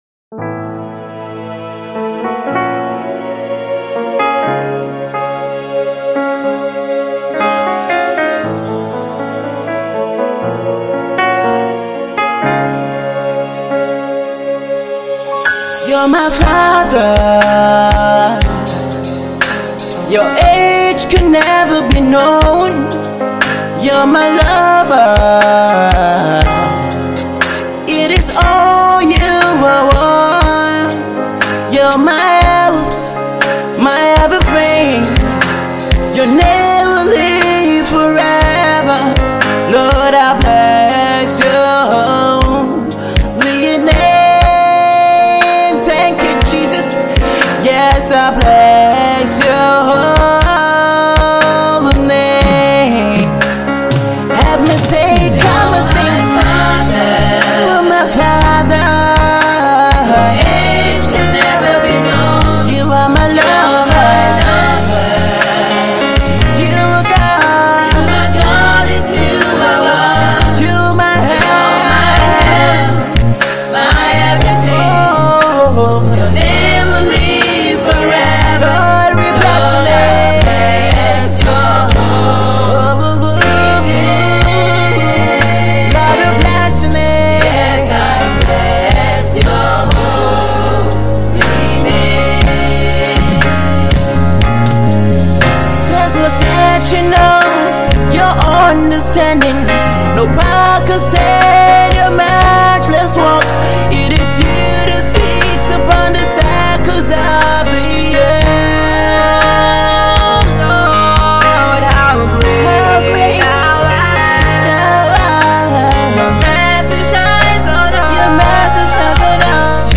worship single